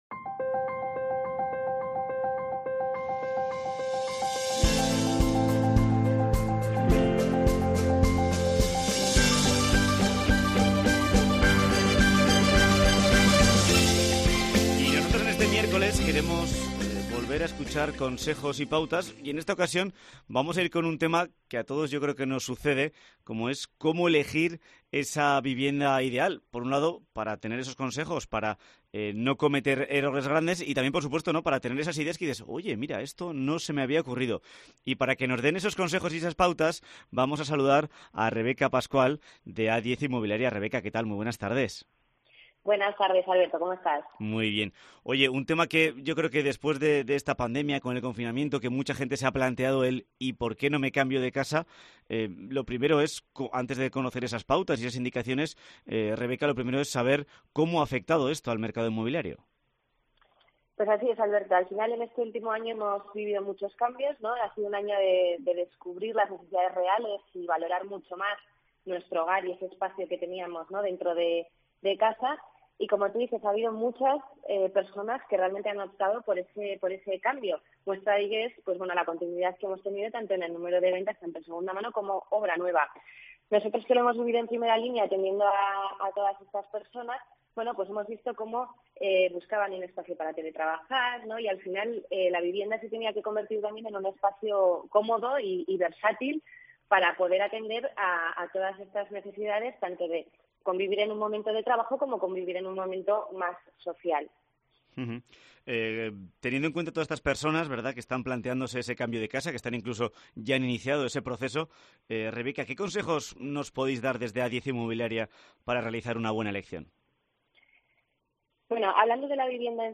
Entrevisca